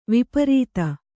♪ viparīta